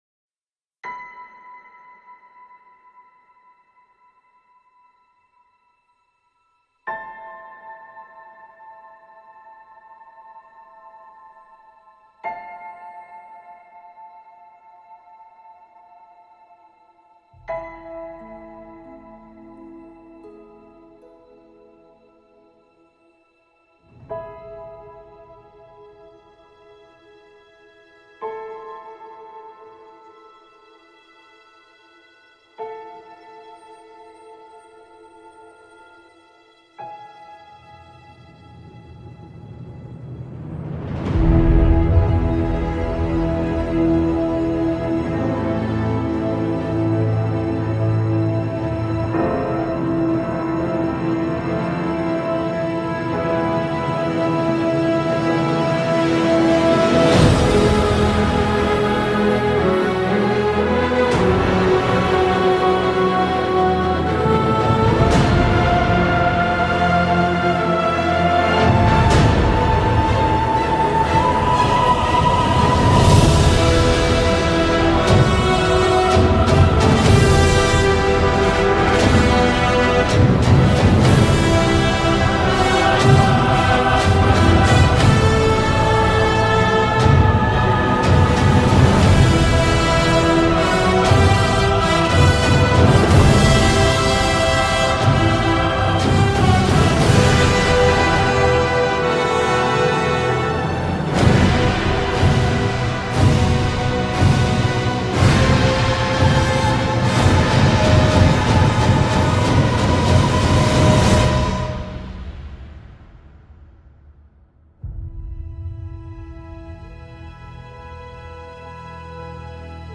theme song mix